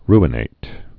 (rə-nāt)